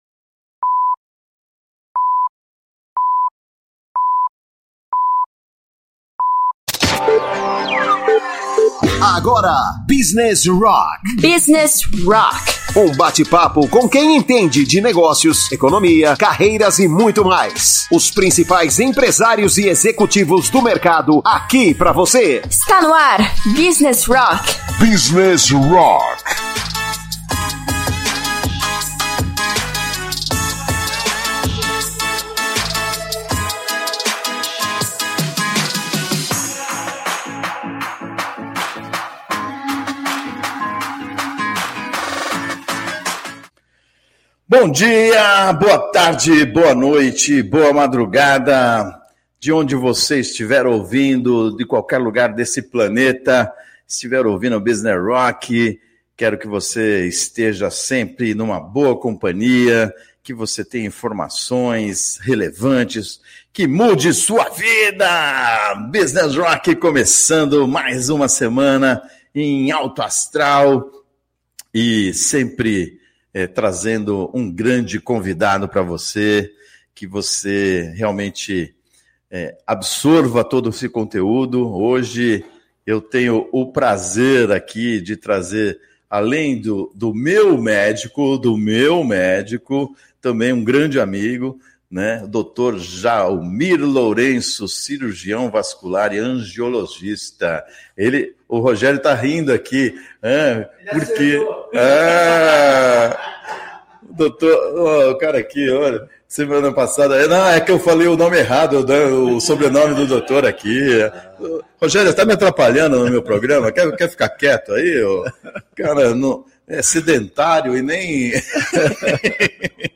Business Rock entrevista